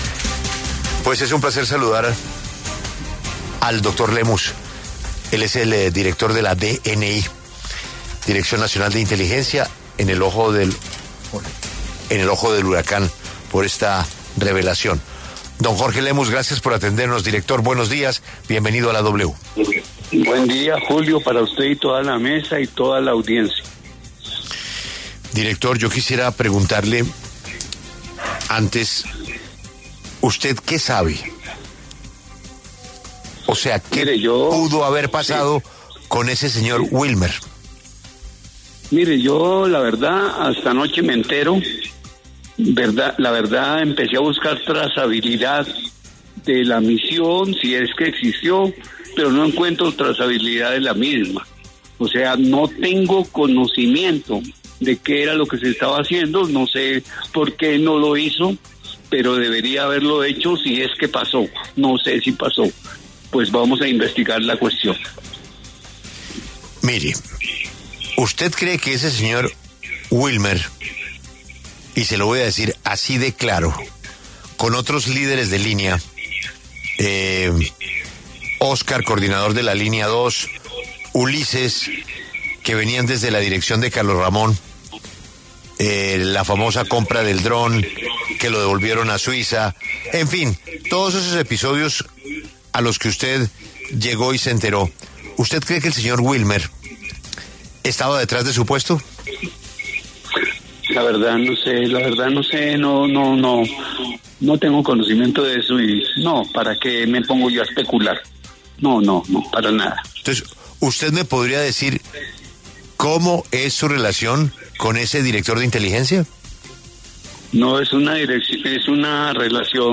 Jorge Lemus, director de la Dirección Nacional de Inteligencia, habló en La W y se pronunció al respecto de la denuncia diciendo que no conocía de la información.